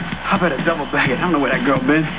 FILM QUOTES